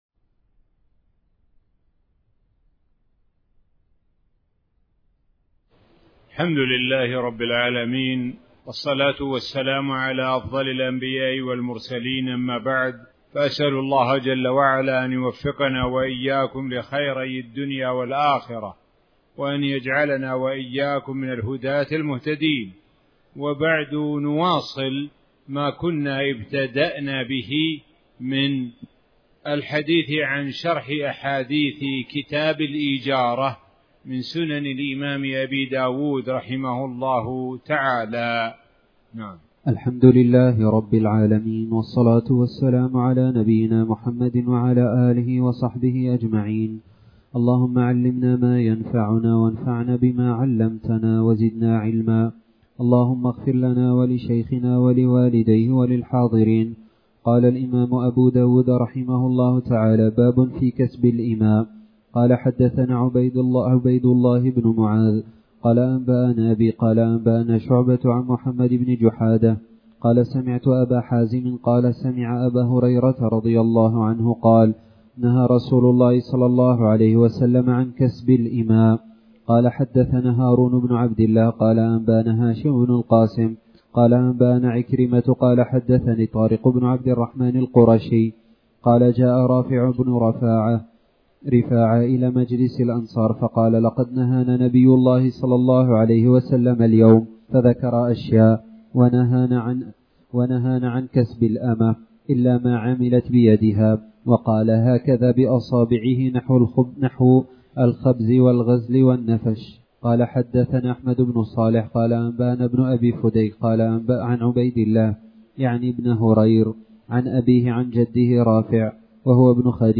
تاريخ النشر ٢١ ذو الحجة ١٤٣٩ هـ المكان: المسجد الحرام الشيخ: معالي الشيخ د. سعد بن ناصر الشثري معالي الشيخ د. سعد بن ناصر الشثري باب في كسب الإماء